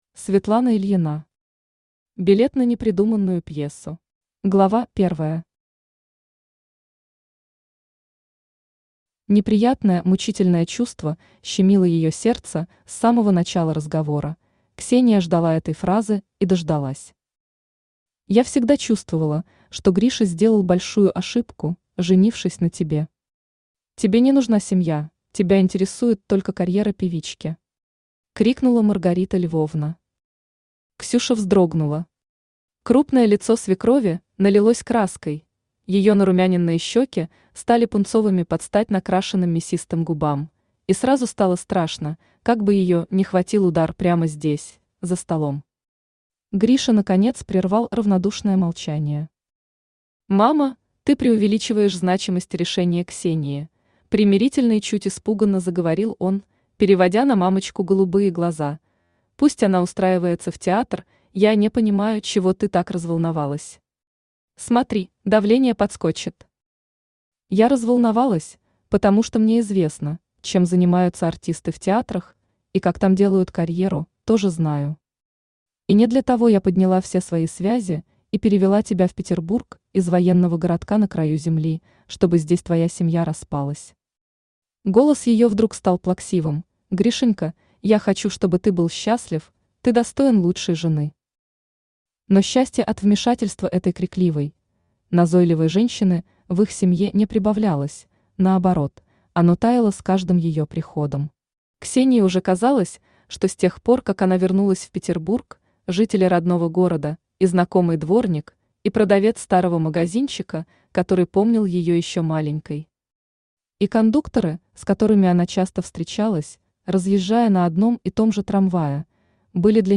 Аудиокнига Билет на непридуманную пьесу | Библиотека аудиокниг
Aудиокнига Билет на непридуманную пьесу Автор Светлана Викторовна Ильина Читает аудиокнигу Авточтец ЛитРес.